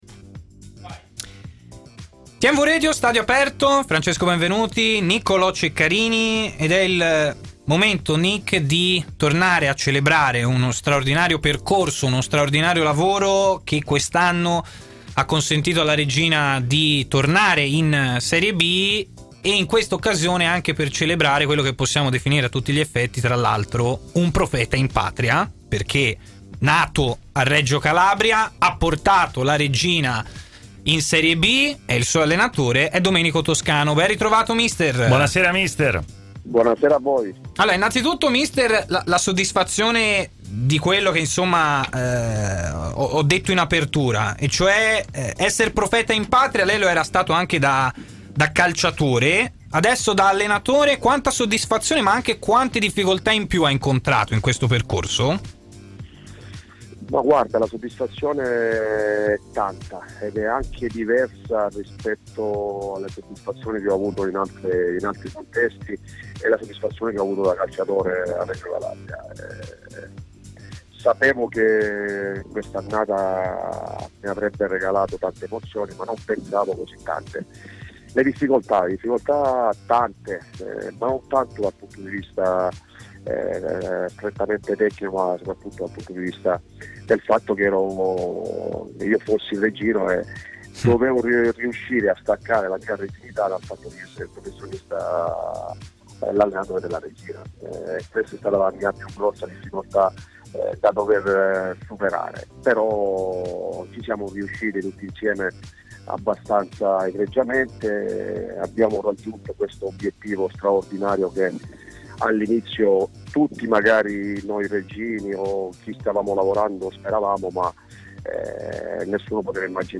ha parlato ai microfoni di TMW Radio, intervenendo nel corso della trasmissione Stadio Aperto